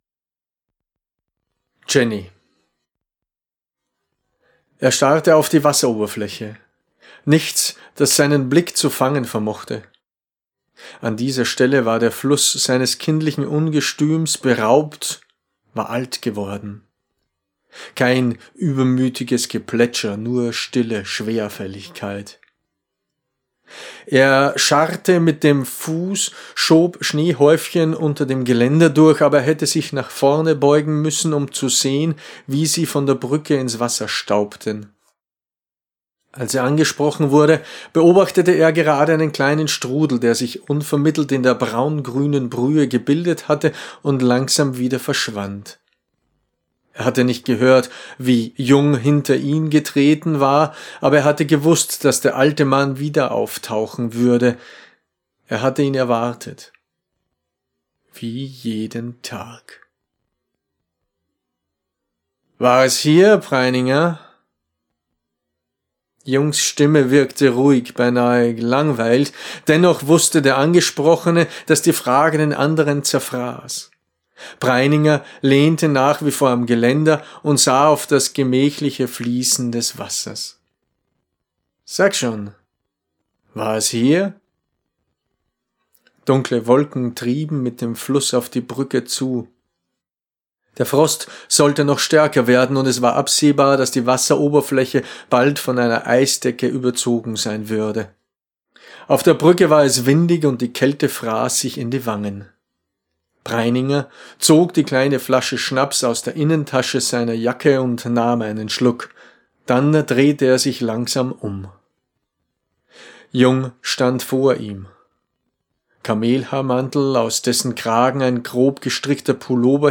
Erzählung